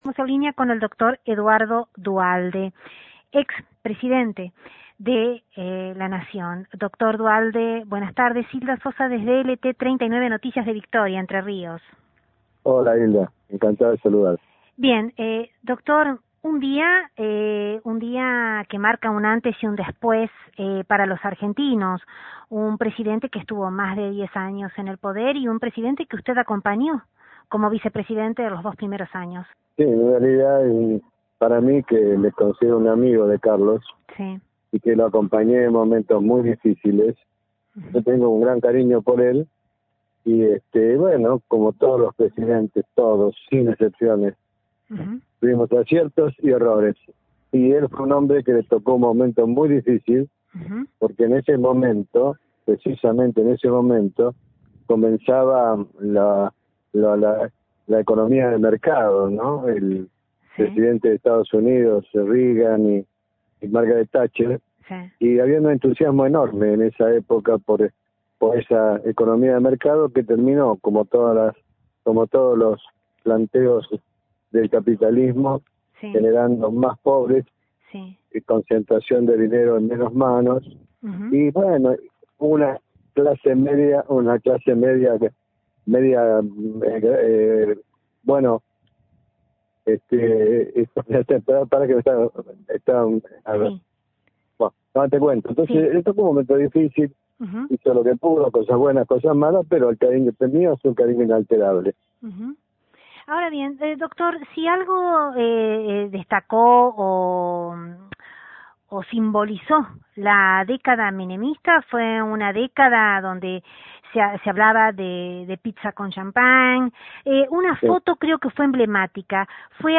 Para recordarlo, desde LT39 NOTICIAS, entrevistamos minutos antes de su ingreso al Congreso, donde era velado el ex primer mandatario, a quien lo acompañara en la fórmula presidencial, que arribara a La Rosada, en las elecciones de 1989; Doctor Eduardo Duhalde (Presidente interino de la Nación (2002-2013), vicepresidente (1989-1991) y gobernador de la provincia de Buenos Aires (1991-1999).